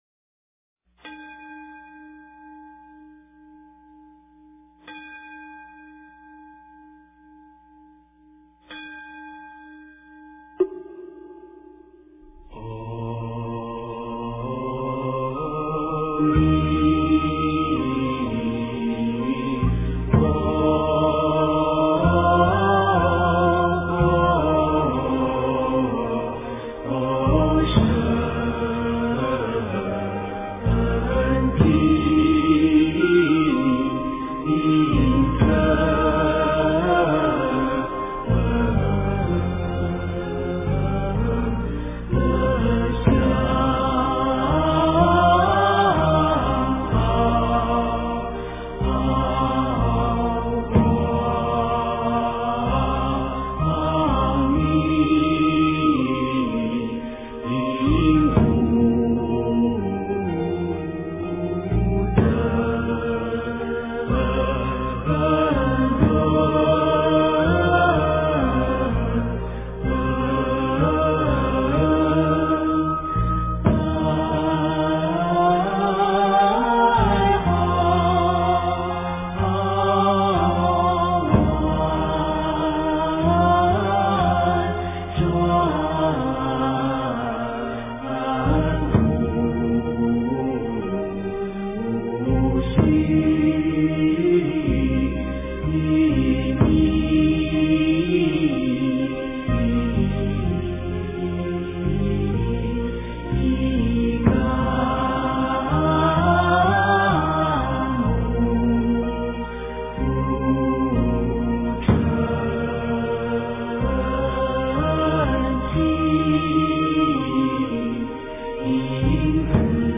即心念佛--佛音 经忏 即心念佛--佛音 点我： 标签: 佛音 经忏 佛教音乐 返回列表 上一篇： 南无阿弥陀佛--印光大师版 下一篇： 忏悔文--女声 相关文章 金光明经-01-念诵 金光明经-01-念诵--未知...